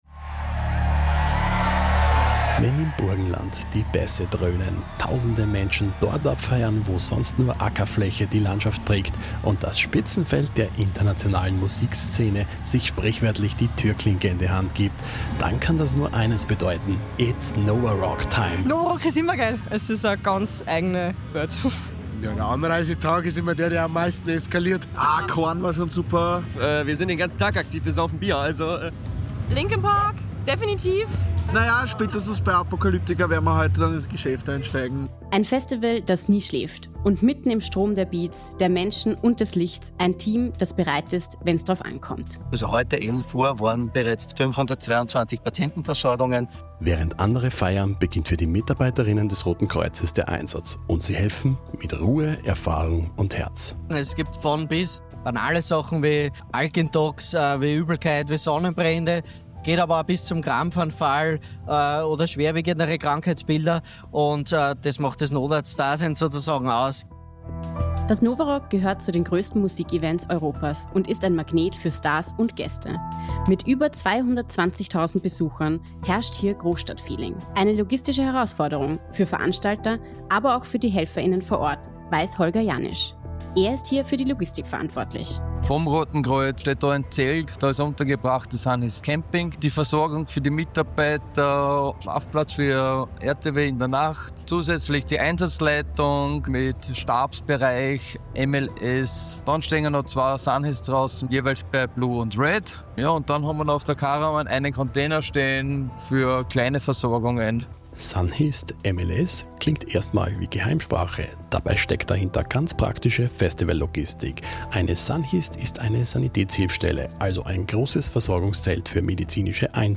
In dieser Reportage vom Nova Rock 2025 begleiten wir Sanitäter:innen, Notärzt:innen und Kriseninterventionsteams durch ihre Einsätze bei Hitze, Bass und Menschenmassen. Wir zeigen, wie sich ein Acker in eine temporäre Großstadt verwandelt – und was passiert, wenn Linkin Park die Bühne rockt und das Festival in voller Wucht lebt.